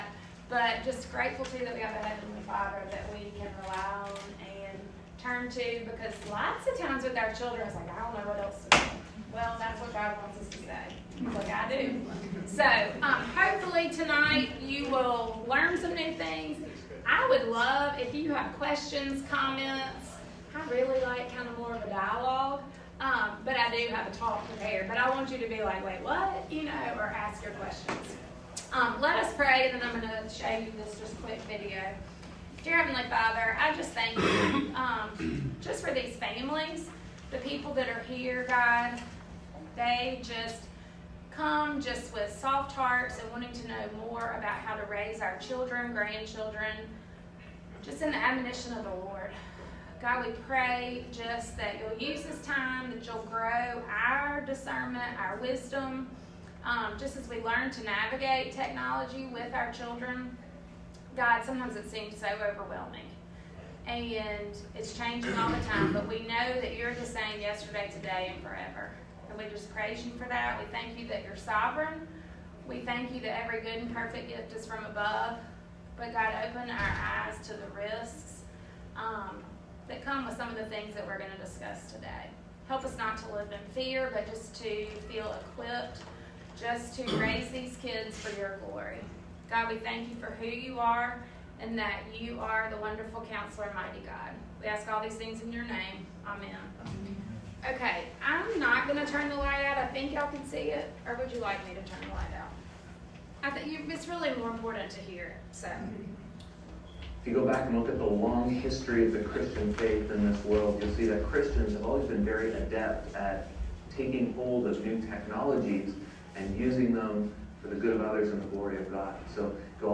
Parenting Seminar 2023